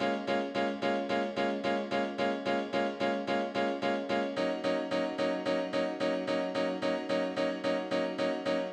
03 Piano PT3.wav